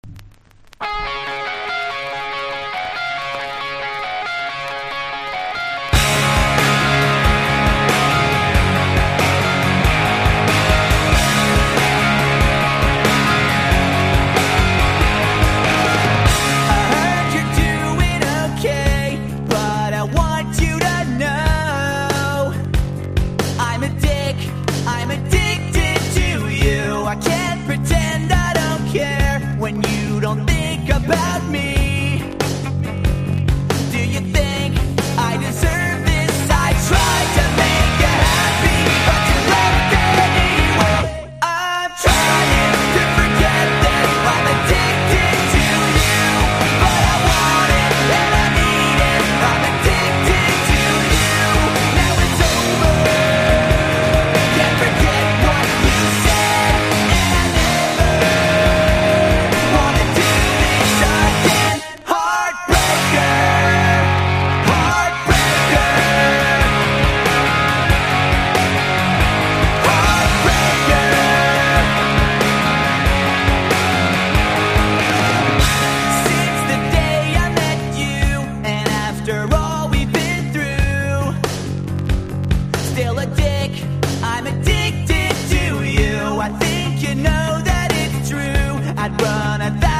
PUNK / HARDCORE